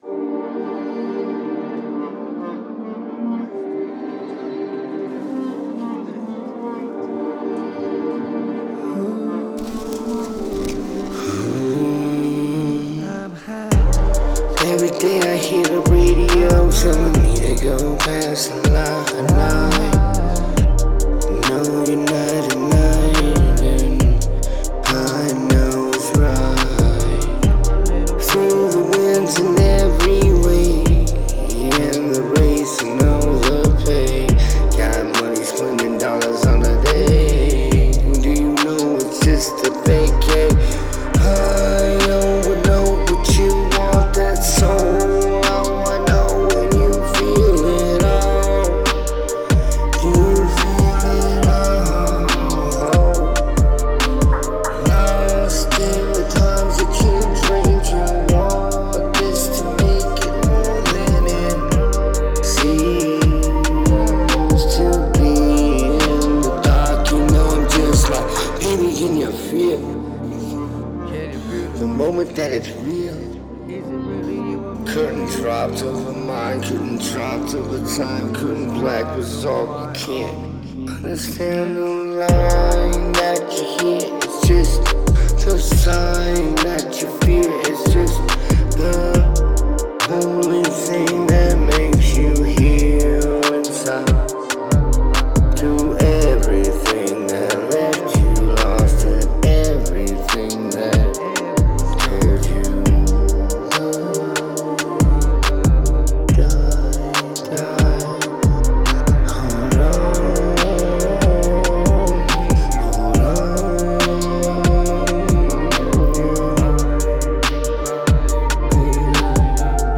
Date: 2026-09-05 · Mood: dark · Tempo: 91 BPM · Key: C major